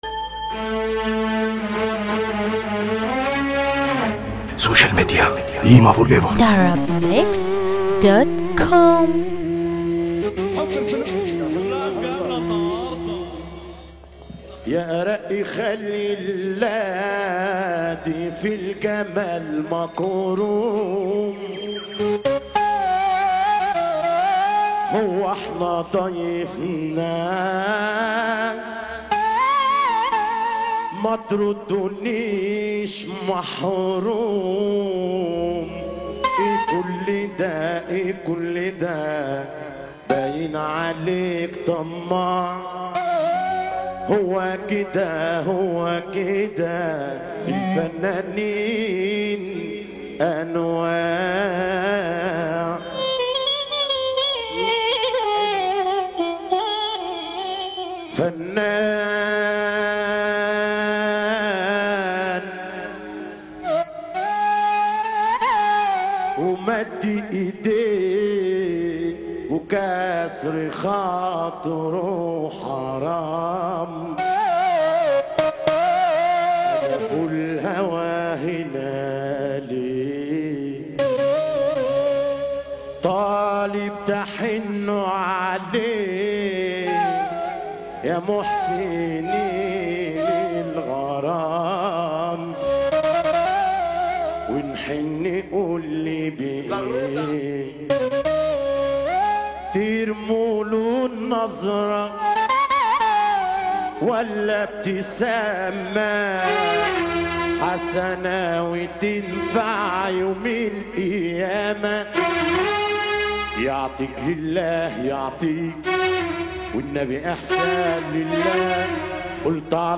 موال
باقوى احساس